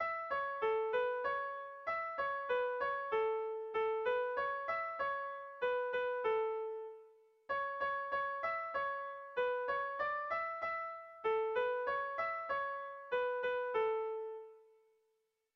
Lauko handia (hg) / Bi puntuko handia (ip)
A1A2